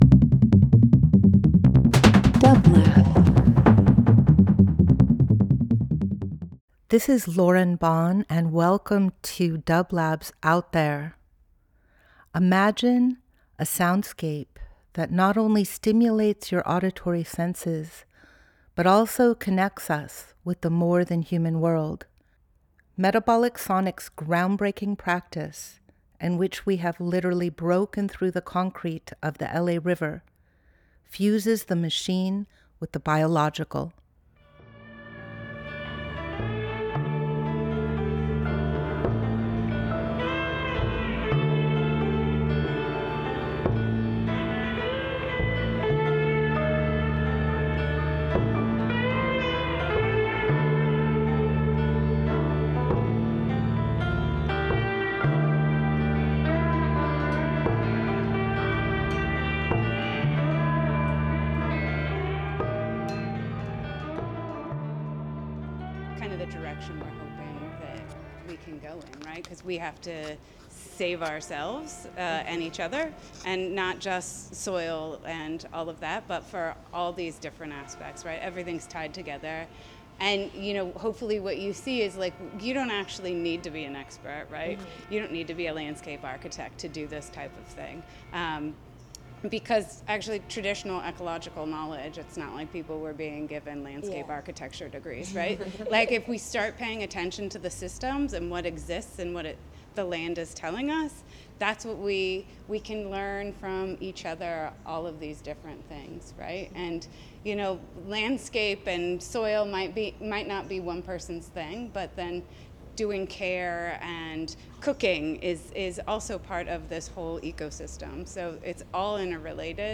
Out There ~ a field recording program
Each week we present a long-form field recording that will transport you through the power of sound.
The Moon Tour, Part Two – This is a continuation of an introduction to, and a community discussion about Metabolic Studio’s project Moving Mountains, which redistributes healthy topsoil from landslides in the art form Meandros. This discussion took place on June 27, 2025 at a tour of “The Moon”, a property that has been un-developed and is supporting native plant re-growth. The discussion is interspersed with improvised music by Metabolic Sonics.